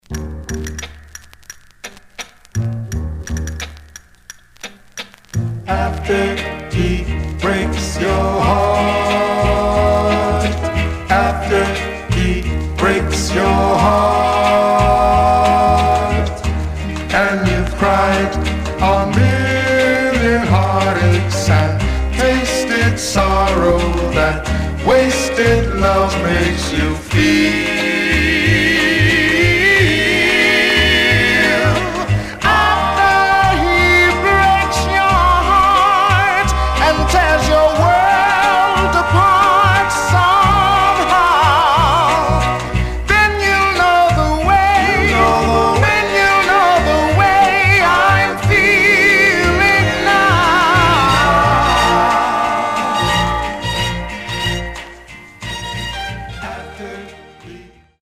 Surface noise/wear
Mono